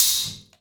Cymbol Shard 15.wav